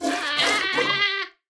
Minecraft Version Minecraft Version latest Latest Release | Latest Snapshot latest / assets / minecraft / sounds / mob / goat / screaming_milk5.ogg Compare With Compare With Latest Release | Latest Snapshot
screaming_milk5.ogg